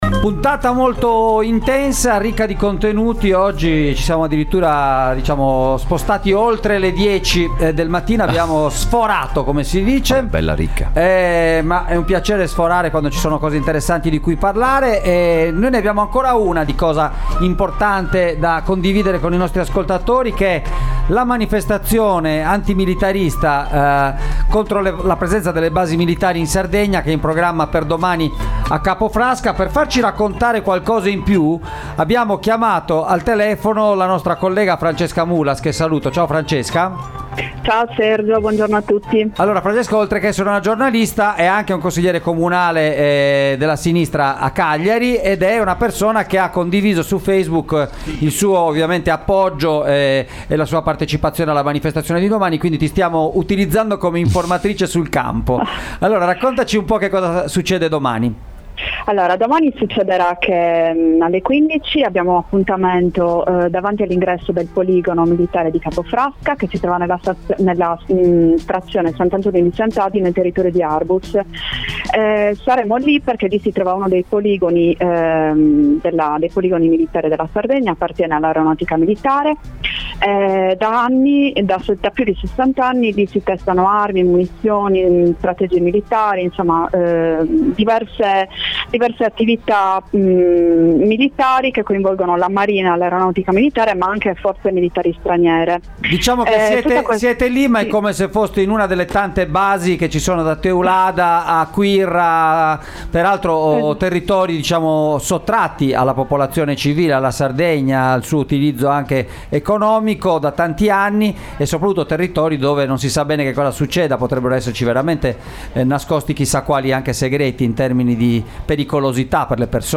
Ne abbiamo parlato all’interno di Extralive mattina in collegamento telefonico con Francesca Mulas, giornalista e consigliera del Comune di Cagliari.